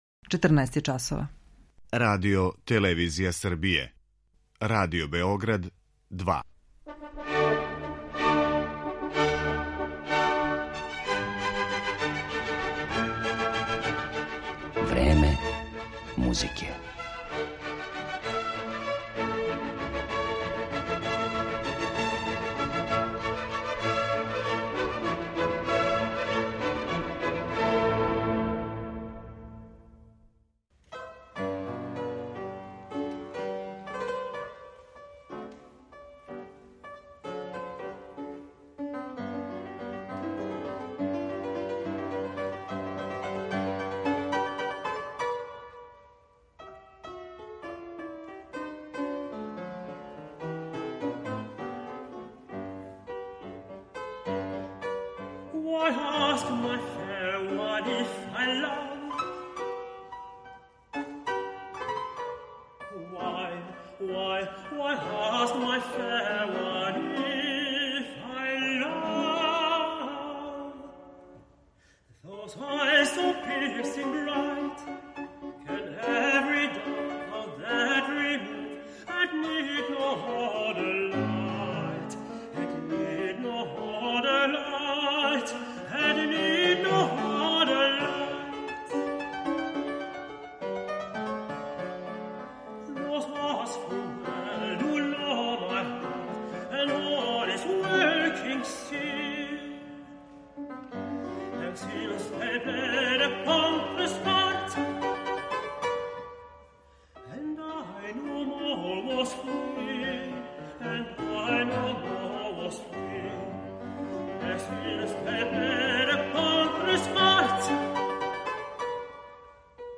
Многима је податак да је Јозеф Хајдн готово читавог живота писао песме за глас и клавир сасвим непознат, јер се овај, не тако мали део његове заоставштине и данас ретко проналази на концертним програмима и снимцима.
белгијски тенор
бугарског пијанисту